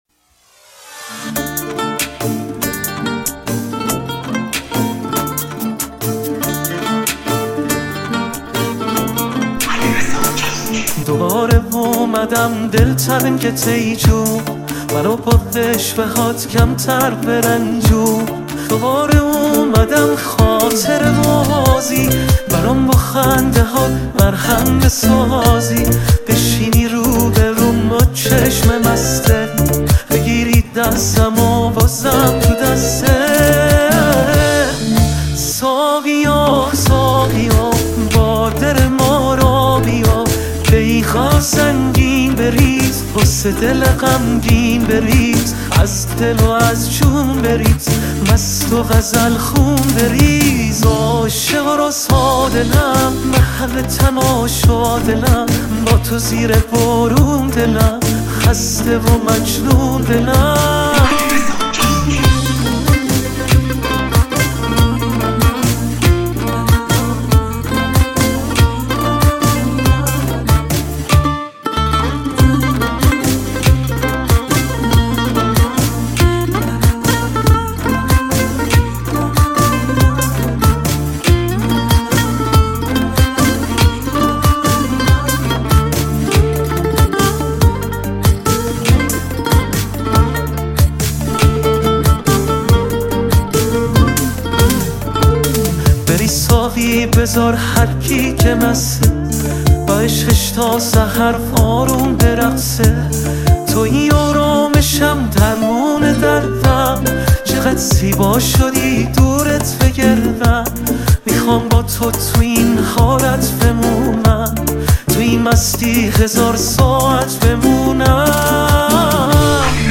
پاپ ایرانی